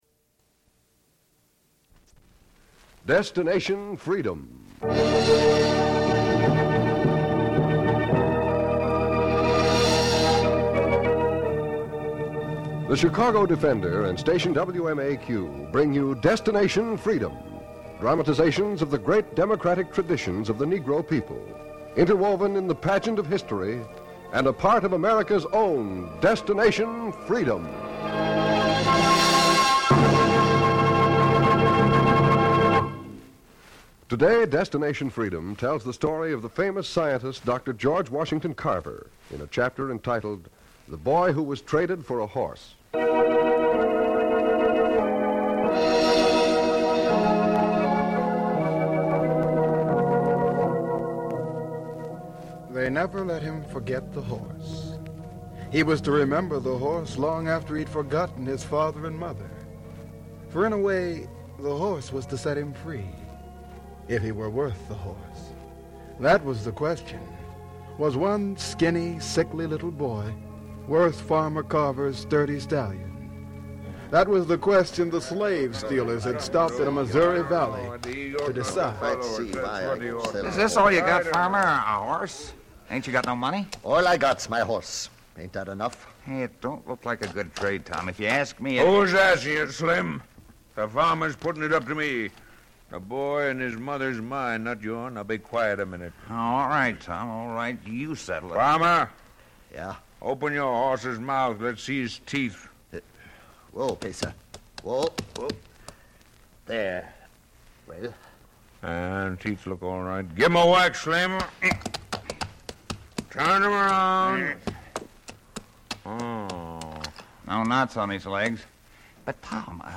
**"Destination Freedom"** was a pioneering radio series written by **Richard Durham** that aired from 1948 to 1950.